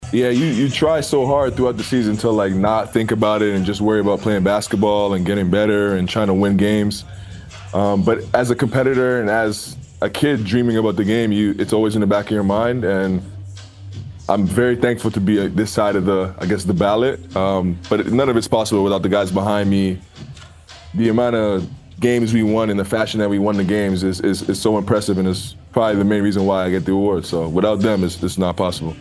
SGA had a press conference and then an interview live on TNT after the announcement.